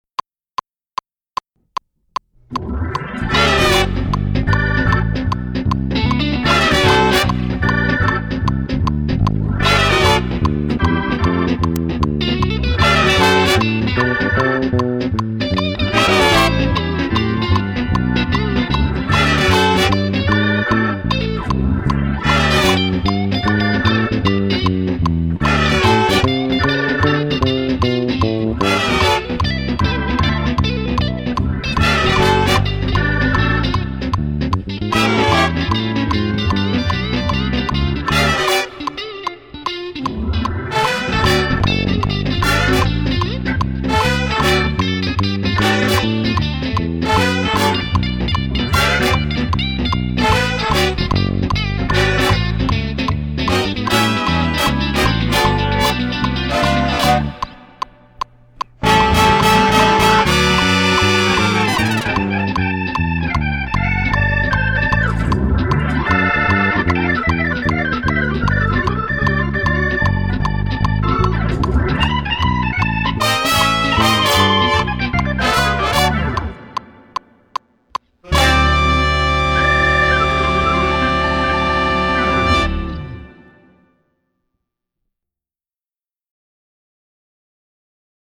PLAY ALONG: SHUFFLE
Texas Shuffle
without Drums: